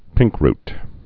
(pĭngkrt, -rt)